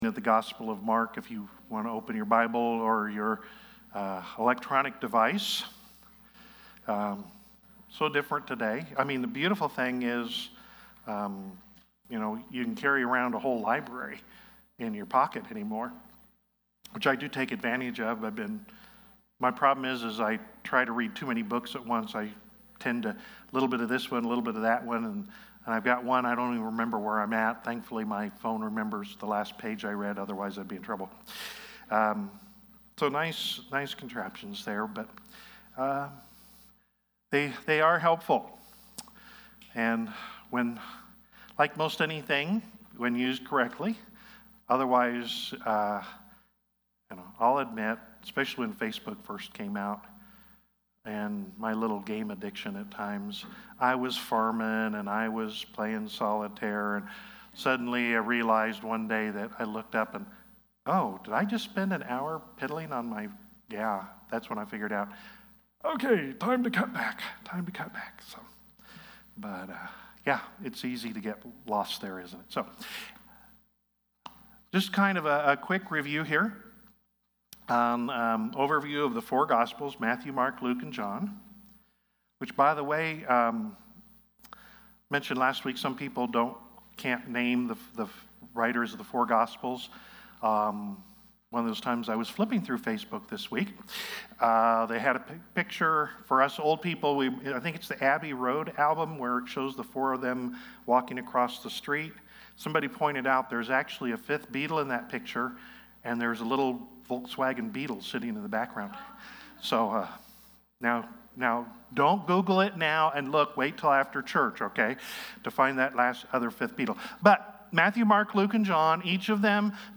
Sermons | Friendship Assembly of God